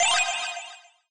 open_ui.mp3